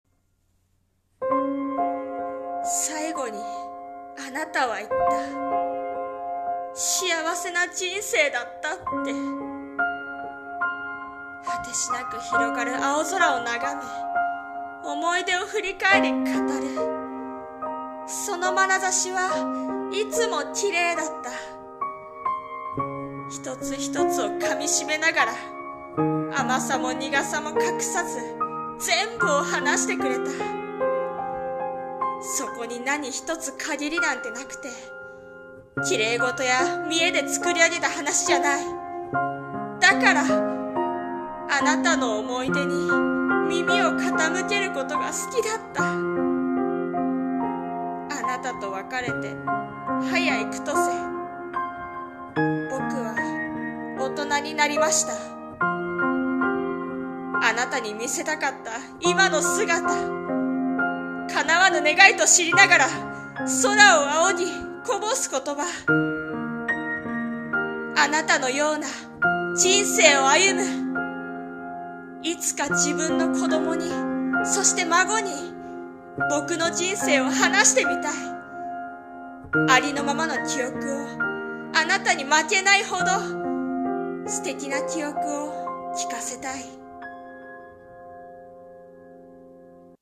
さんの投稿した曲一覧 を表示 声劇【早幾年】